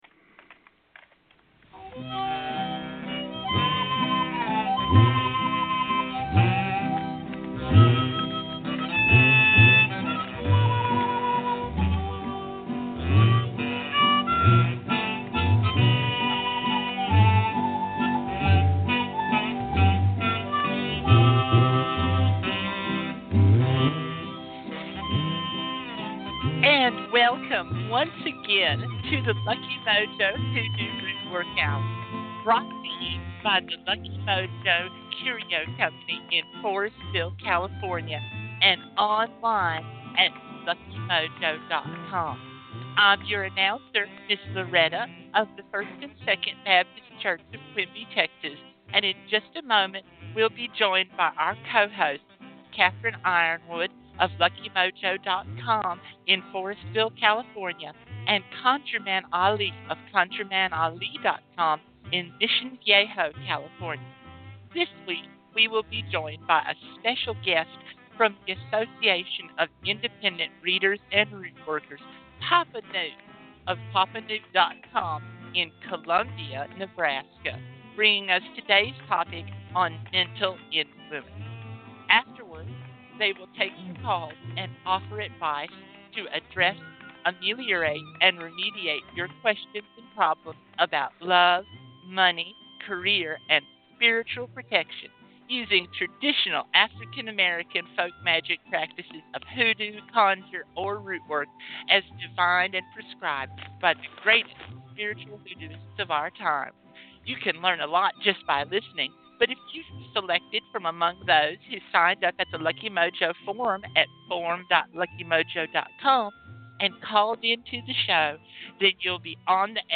After this we provide free readings, free spells, and conjure consultation, giving listeners an education in African American folk magic.